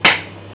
bouncehard3.wav